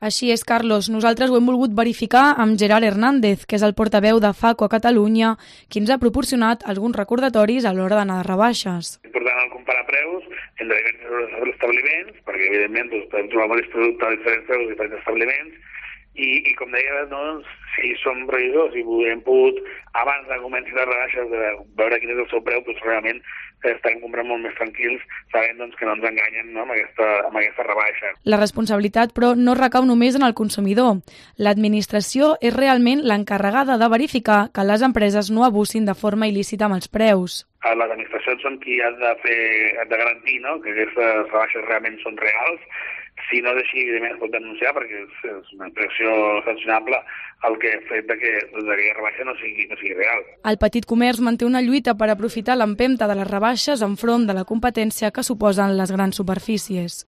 "Realmente no considero que hagan un descuento muy grande, podrían tener mejores rebajas" se lamentaba una joven que se encontraba por la zona de tiendas del centro de Barcelona.